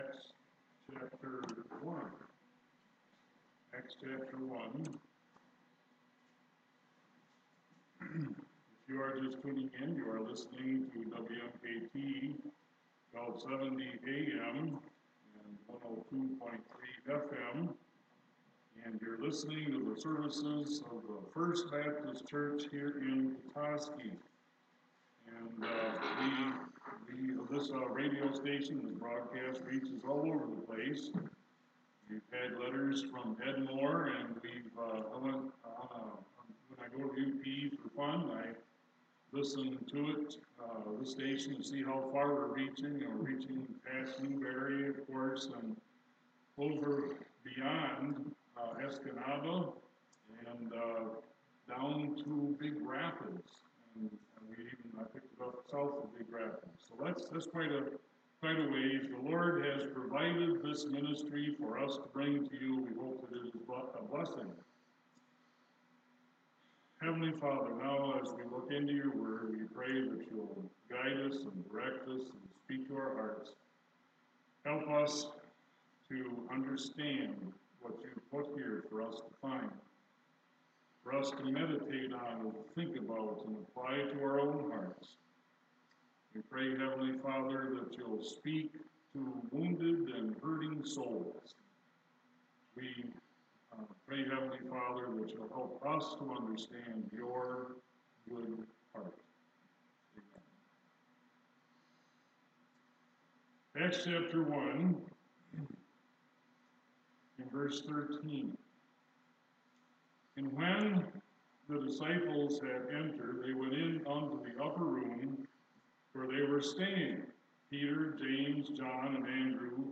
Sunday Morning Message – June 9, 2019 | First Baptist Church of Petoskey Sunday Morning Bible Teaching
Sunday Morning Message – June 9, 2019